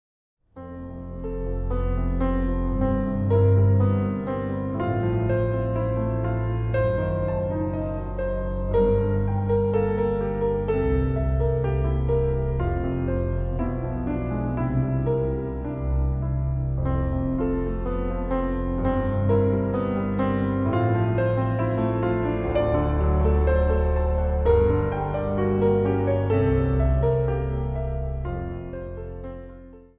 Instrumentaal | Orgel
Instrumentaal | Piano